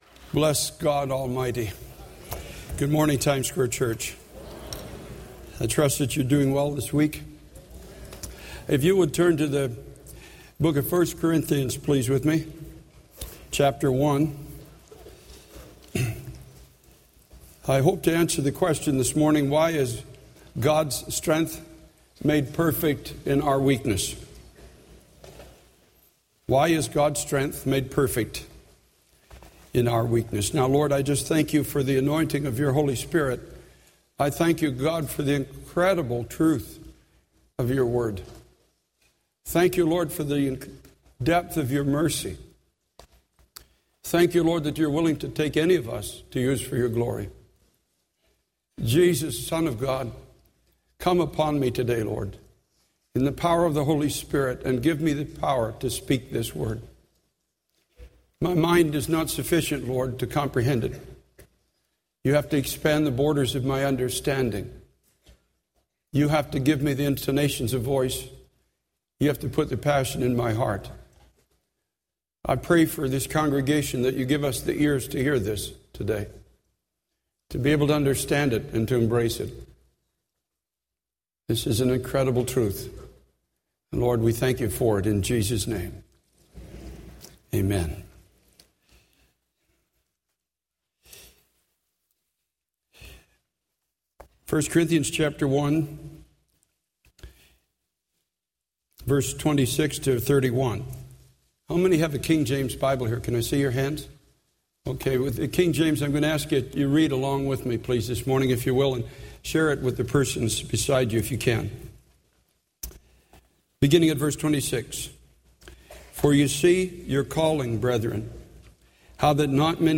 In this sermon, the preacher emphasizes the message that through the shed blood of Jesus Christ, believers are set free from the power of sin.